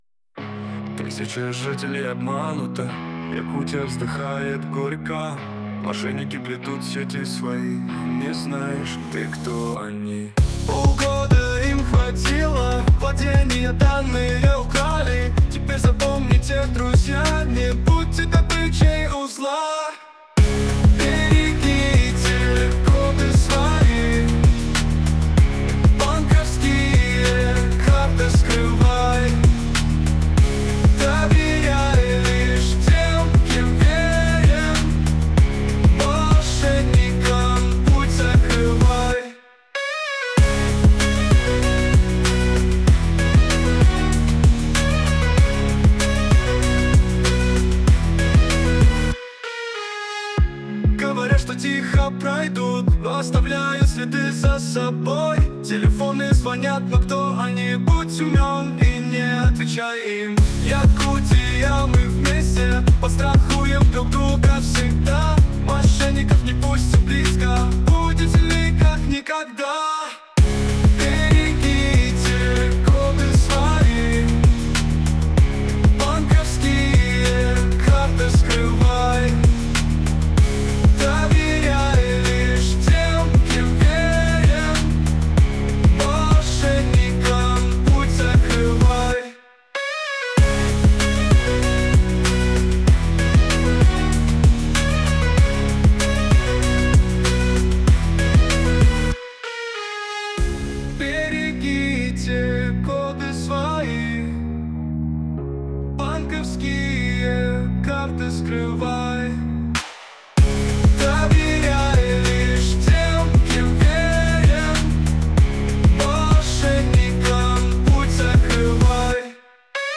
Полиция Якутии с помощью нейросети выпустила песню о борьбе с мошенниками